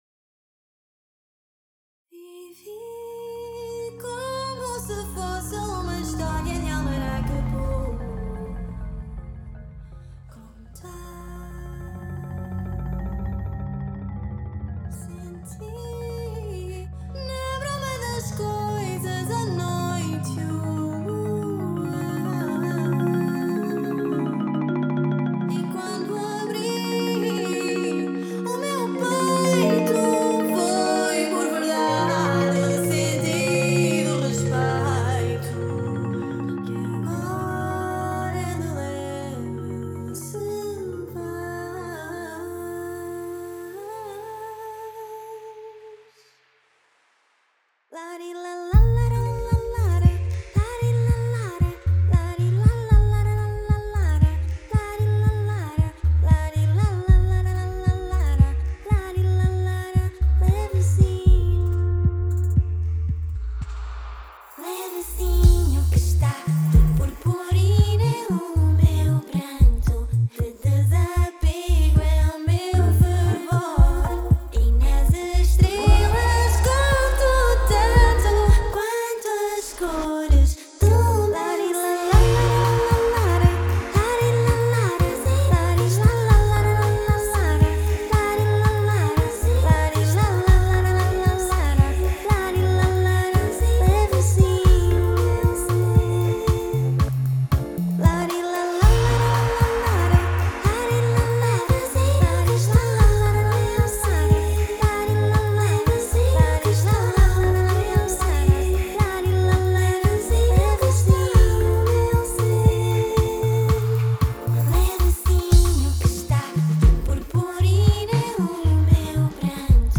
vocals
instrumental